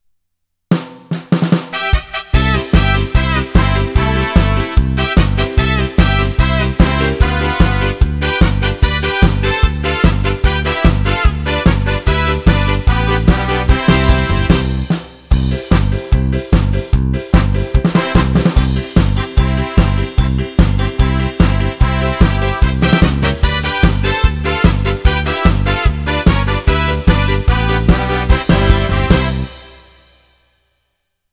KORG PA4x MUSIKANT stílus KETRON SD40-en! :lol:
GARDENPOLKA.WAV